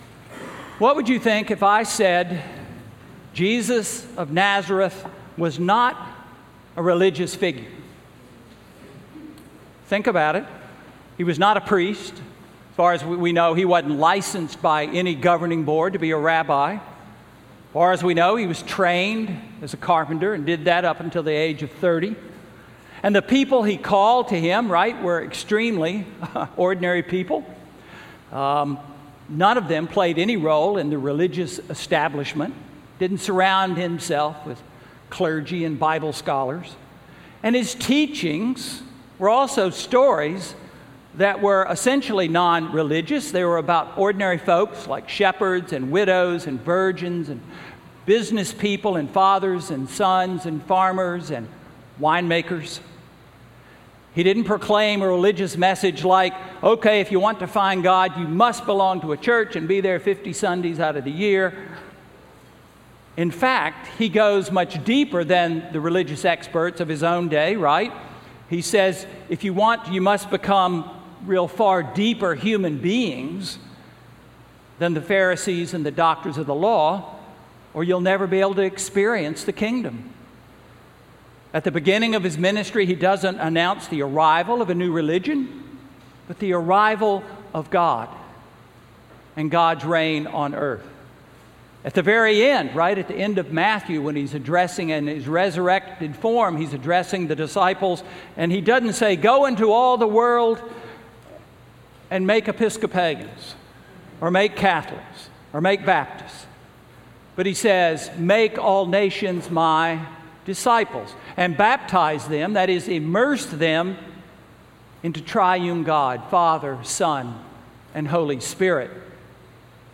Sermon–September 18, 2016